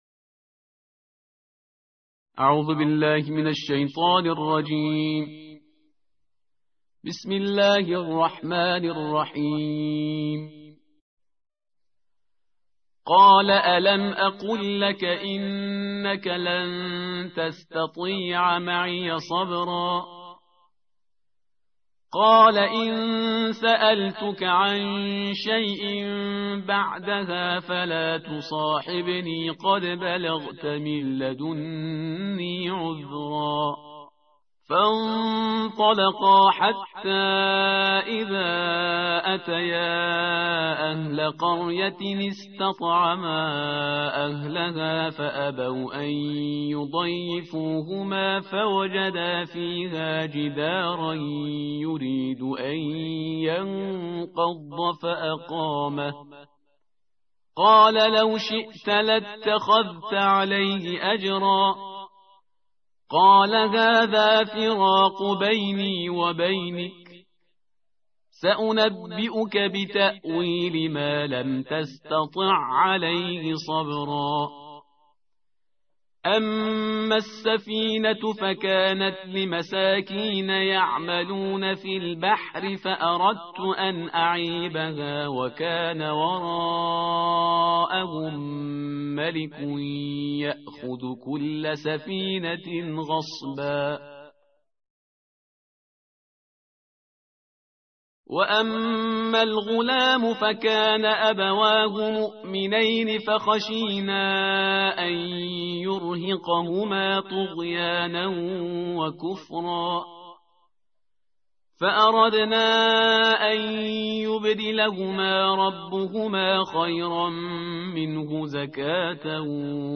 ترتیل «جزء شانزدهم» قرآن کریم